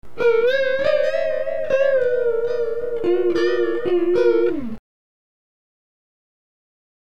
Oscillator = sine
Oscillator Freq = 3 Hz
Delay time = 20 ms
Depth = 3 ms
Wet Gain = 1
Add Chorus
clip16_6s_chorus2.mp3